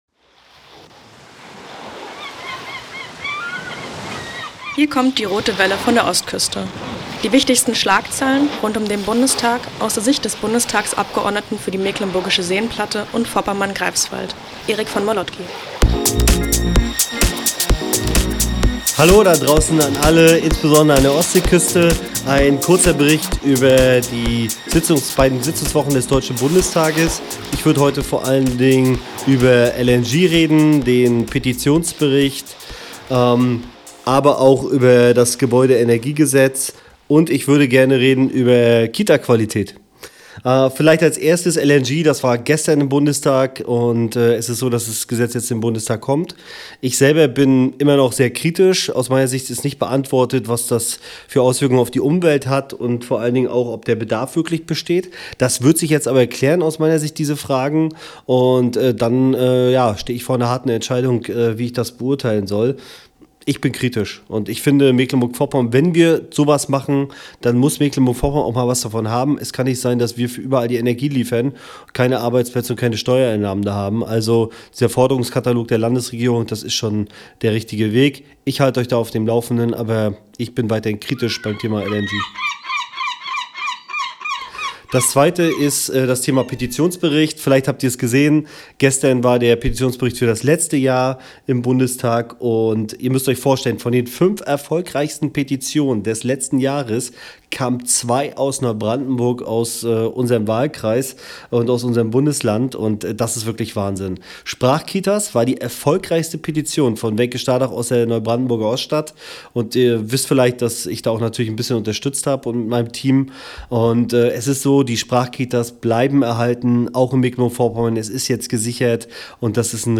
Mit einem Hauch von Stolz in der Stimme erzählt er, dass zwei der fünf erfolgreichsten Petitionen des vergangenen Jahres aus seinem Wahlkreis in Neubrandenburg stammen.